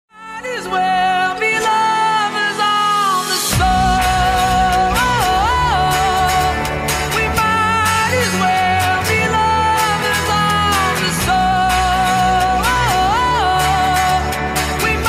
robot music
guitarra